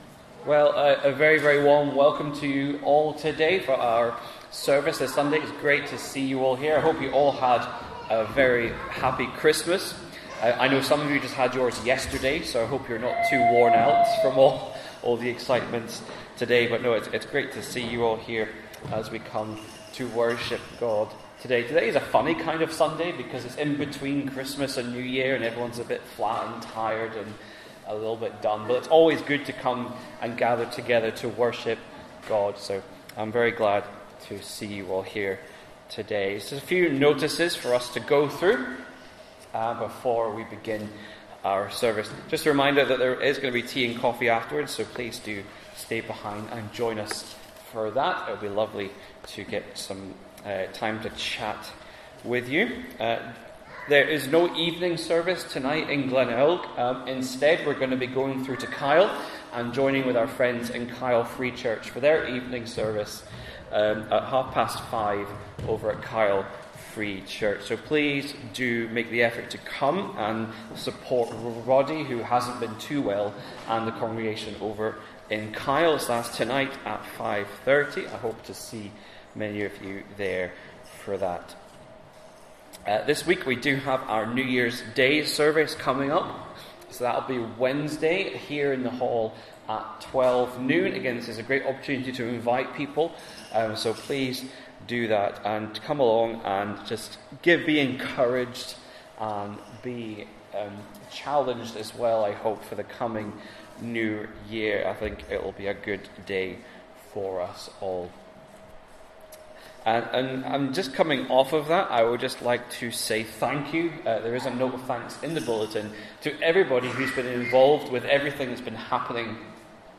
Sunday-Service-29th-December.mp3